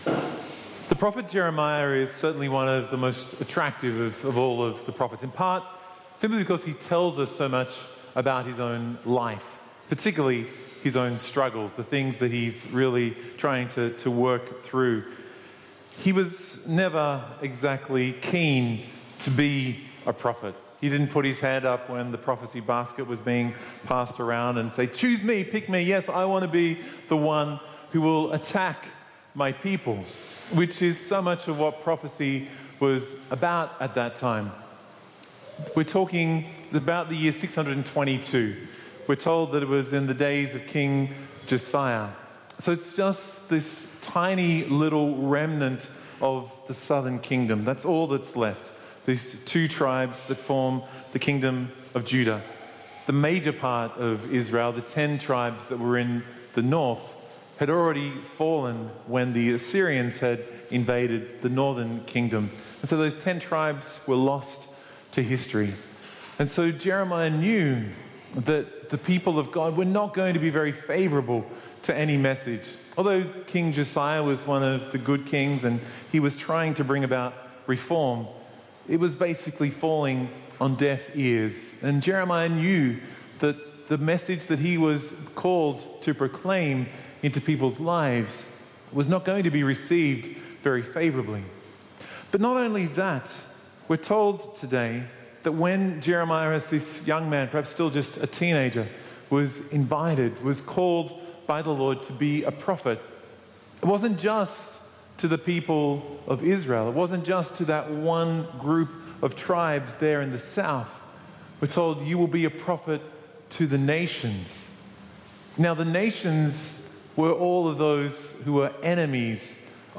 Download or Play MP3 MP3 Audio file (Sunday morning, 9.30am)